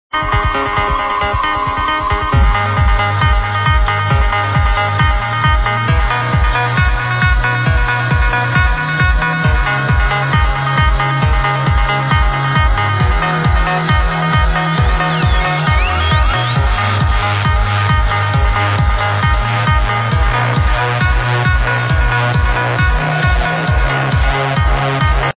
Trance tune ID